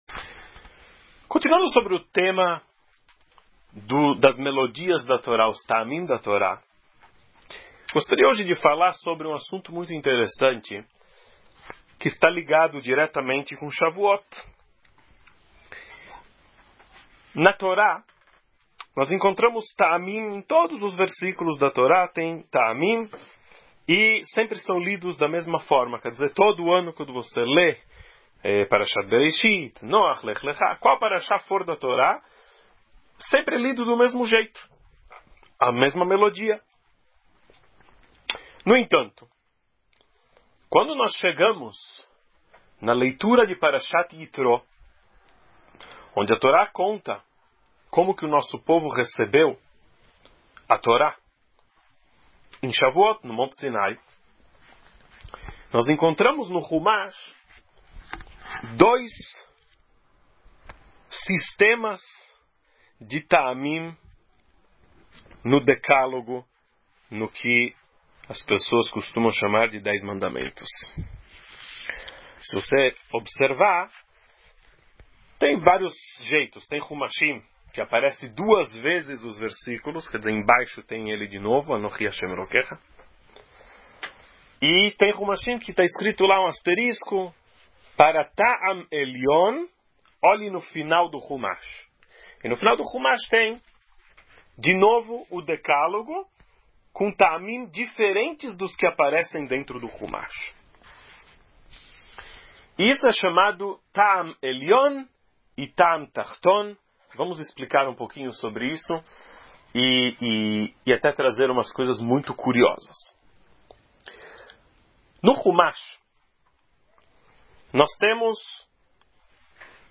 Exemplos da leitura.